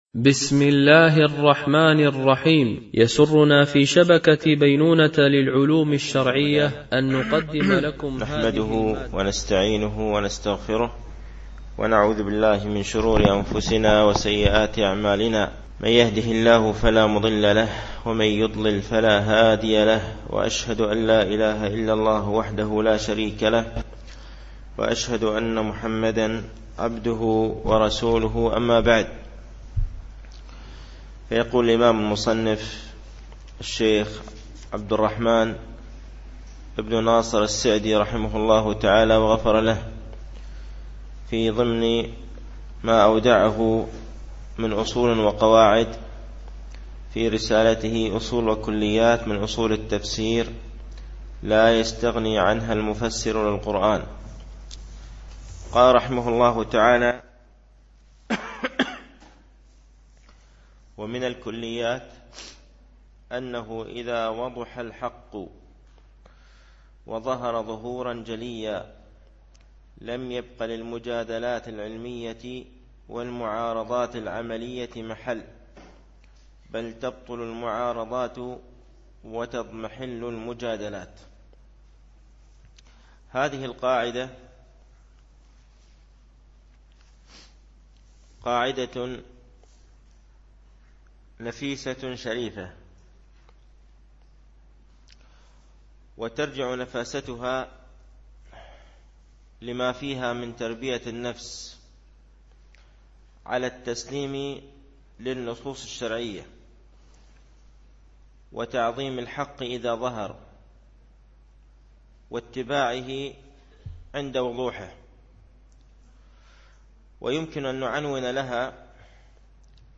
شرح أصول وكليات من أصول التفسير وكلياته للسعدي ـ الدرس الخامس